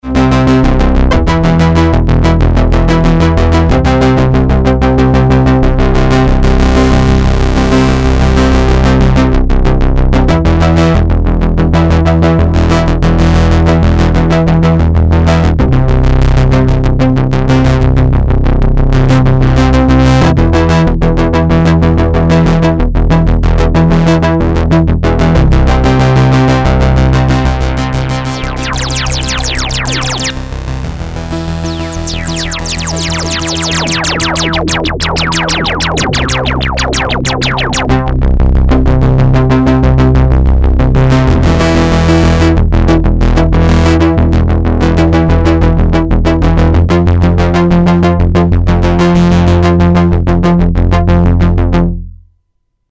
Programmable Preset Polyphonic Synthesizer
internal sequencer